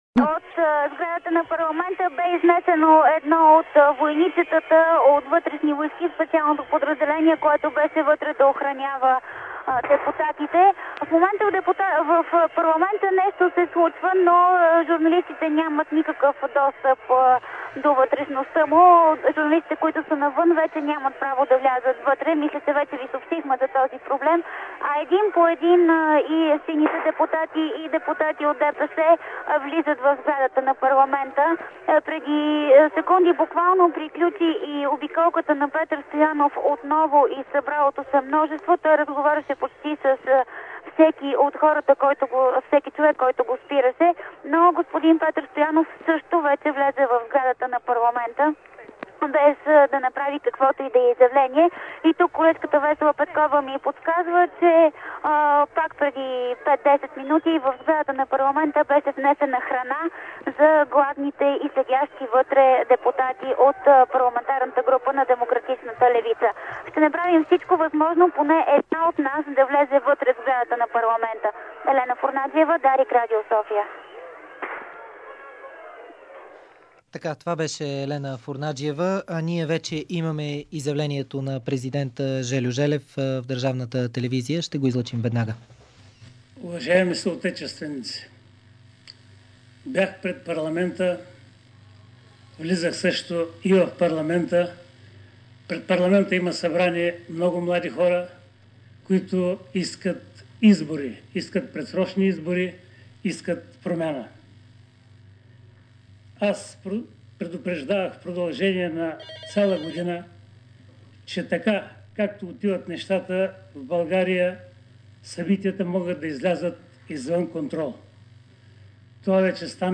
ДАРИК ПРЕДАВА ПРЯКО ПРОТЕСТИТЕ ПРЕД ПАРЛАМЕНТА